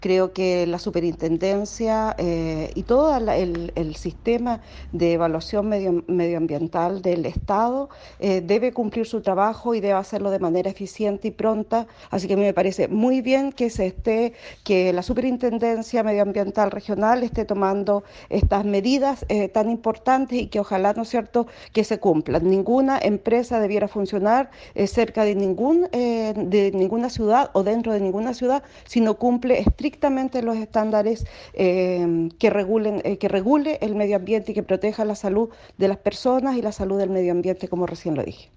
En este sentido la alcaldesa de Paillaco, Ramona Reyes, dijo que la Superintendencia debe cumplir su trabajo y de manera pronta. Sin embargo, la autoridad dijo valorar esta decisión y aseguró que ninguna empresa debería funcionar dentro de la ciudad si no cumple con normativas que respeten la salud y el medio ambiente.